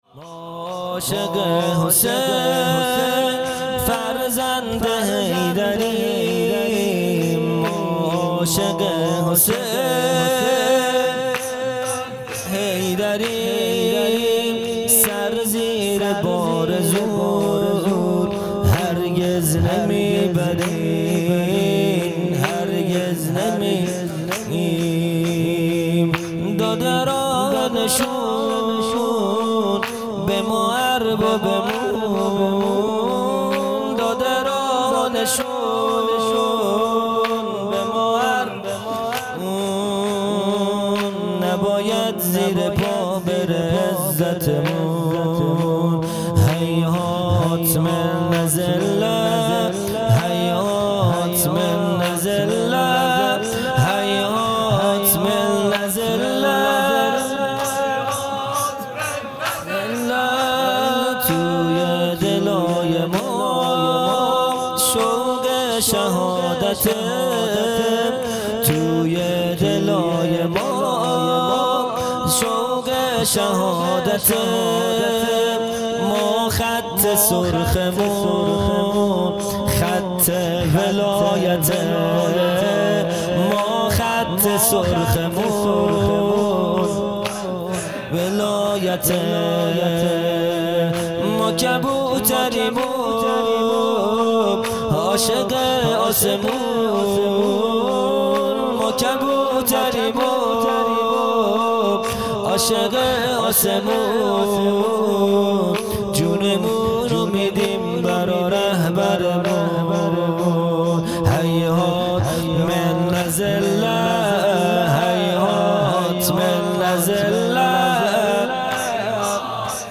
خیمه گاه - هیئت بچه های فاطمه (س) - شور پایانی | ما عاشق حسین، فرزند حیریم
جلسۀ هفتگی